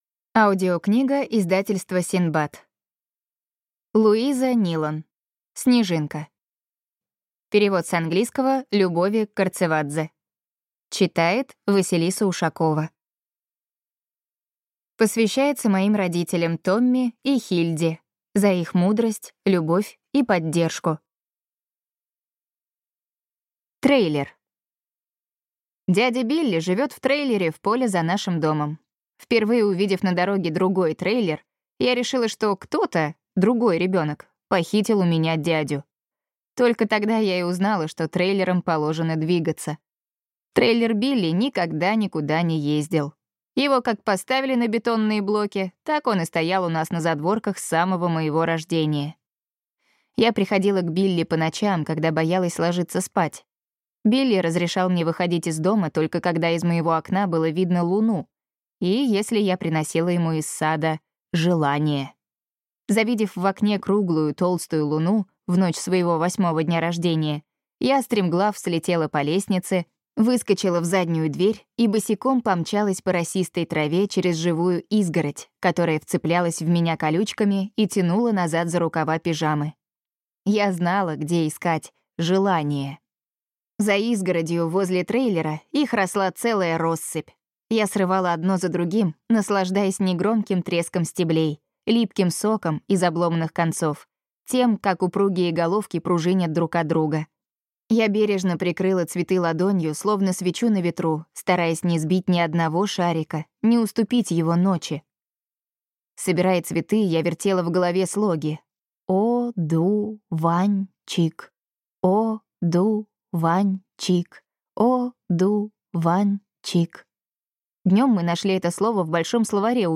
Аудиокнига Снежинка | Библиотека аудиокниг
Прослушать и бесплатно скачать фрагмент аудиокниги